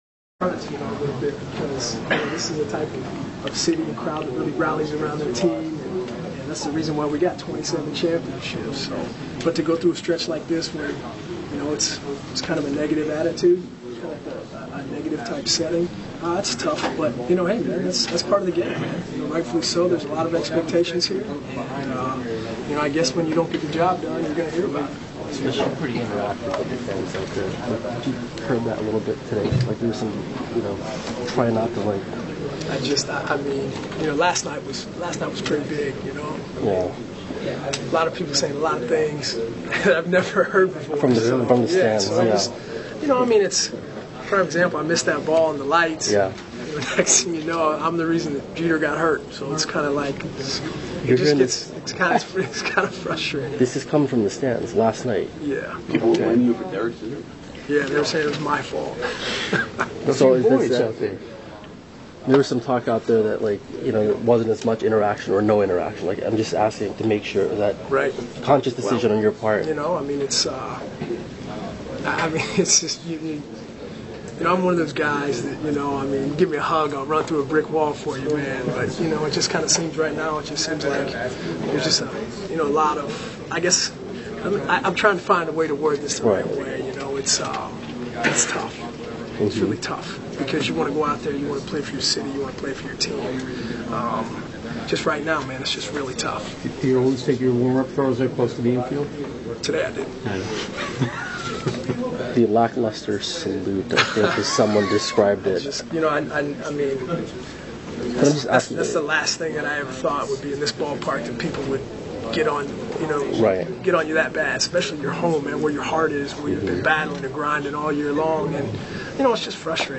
Nick Swisher Clubhouse Audio